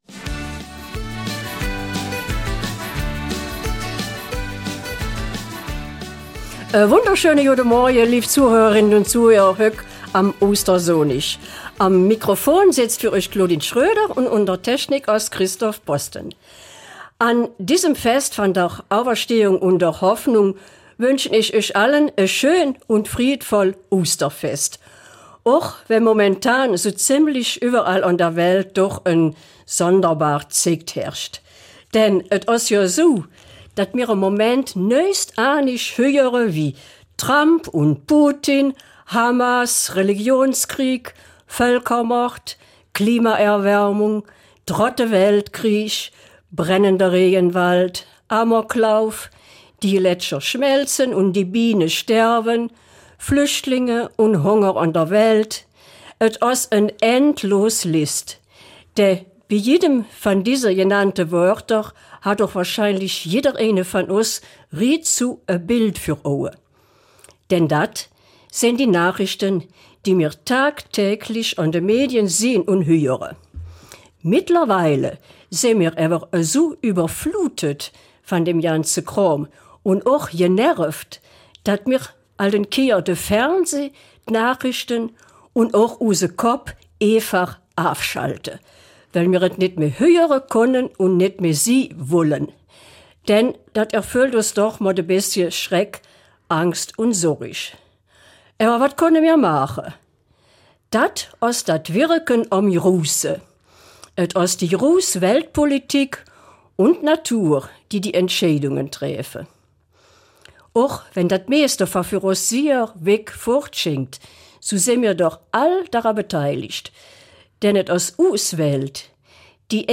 Eifeler Mundart - 20. April